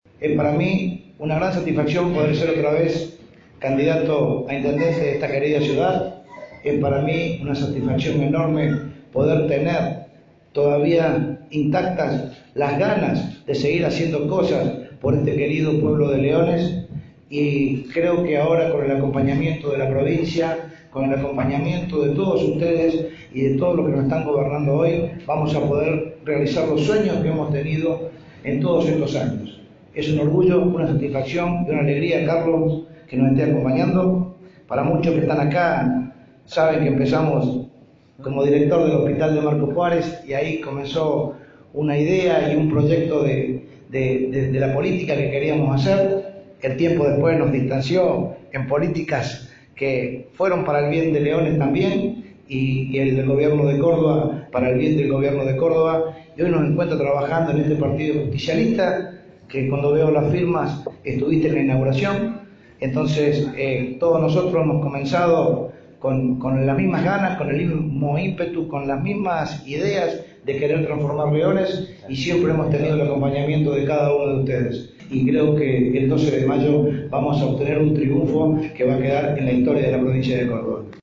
El ministro de gobierno y candidato a legislador provincial, Juan Carlos Massei, acompañado del candidato a intendente de Leones, Fabián Francioni, encabezaron una reunión en la unidad básica de la calle Colón, de la que participaron la intendente Lorena Bussi, los integrantes de la lista local de Hacemos por Córdoba, partidarios y simpatizantes del peronismo.